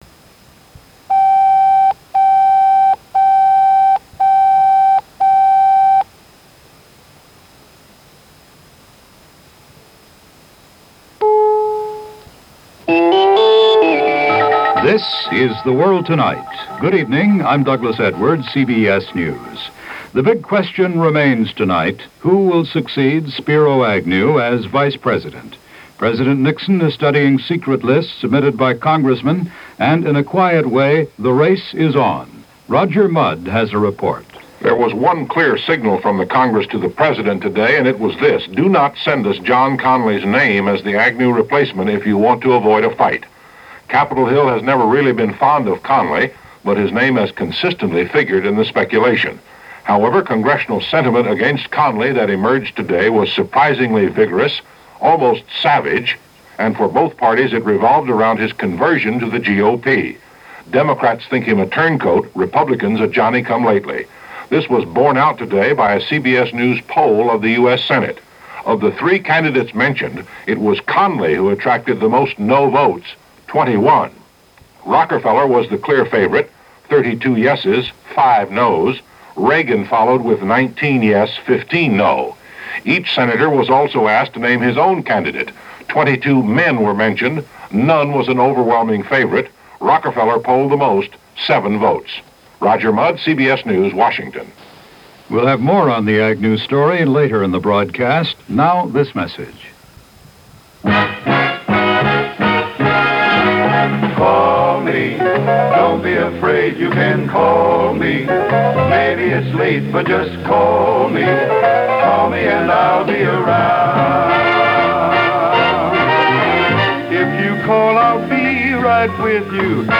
- News from The World Tonight - CBS Radio